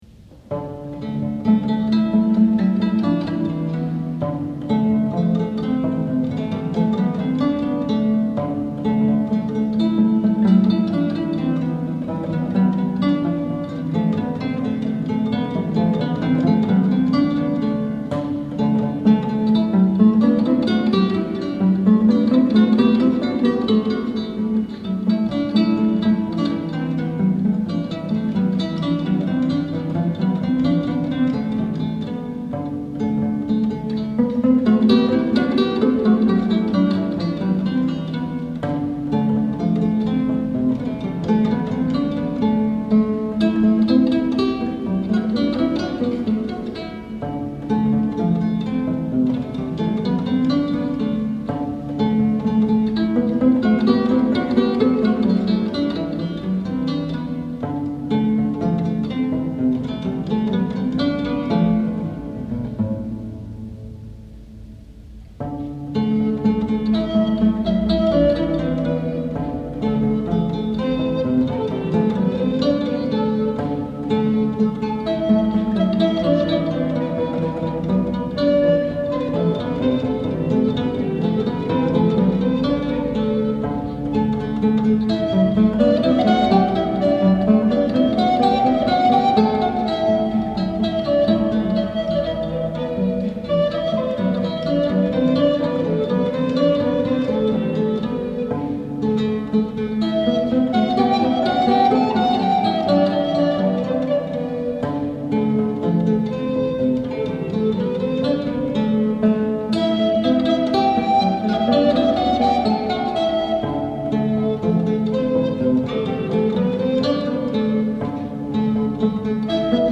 This clever little canon is from an early 17th century ms. in Prague University Library.